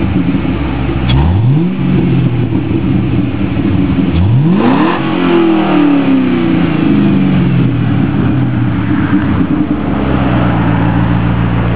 Bassani Exhaust
2002-2003 CADILLAC - AUTO/CTS-V 5.7L